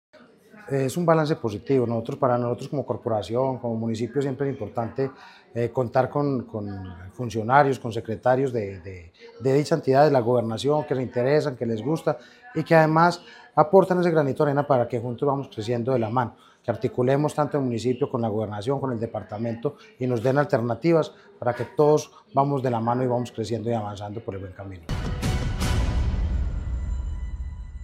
César Morales, Concejal de Chinchiná.
Cesar-Morales-concejal-de-Chinchina-2.mp3